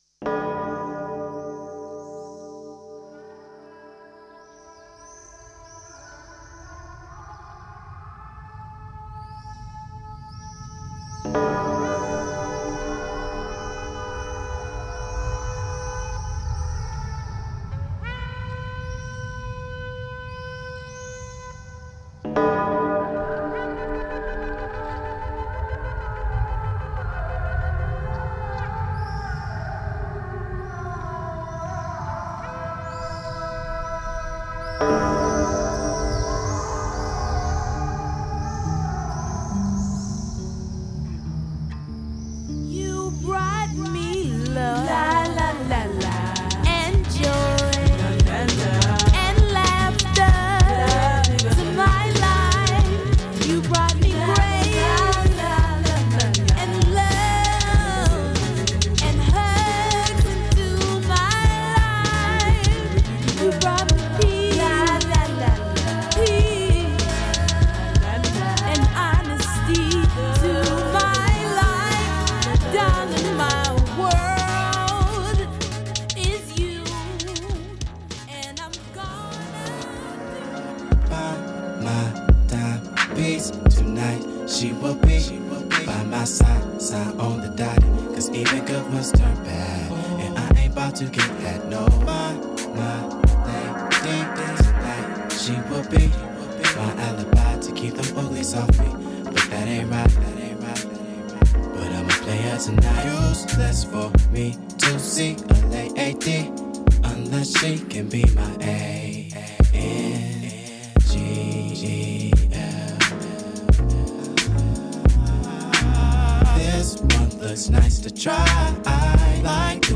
Enjoy this early jazzy hip-hop and R&B electronic mix
Funk/Soul Hip Hop House Jazz R&B Soul